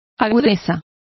Complete with pronunciation of the translation of sally.